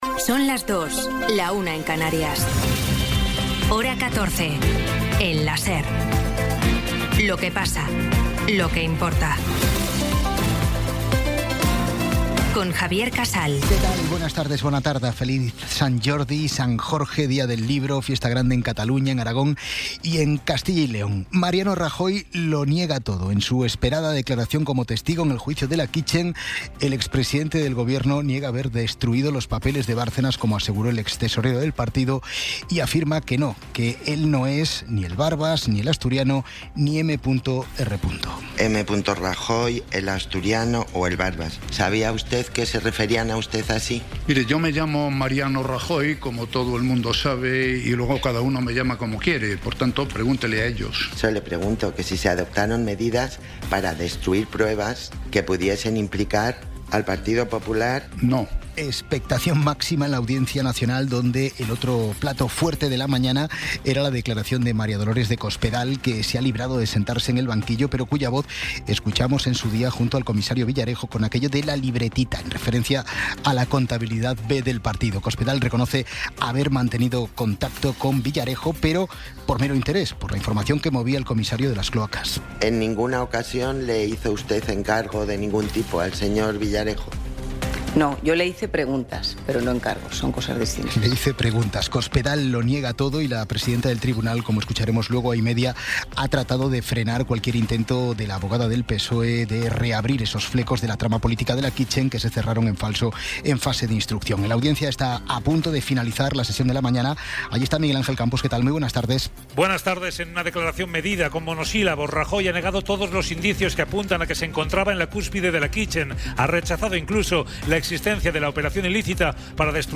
Resumen informativo con las noticias más destacadas del 23 de abril de 2026 a las dos de la tarde.